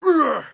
monster / thiefboss1 / damage_2.wav
damage_2.wav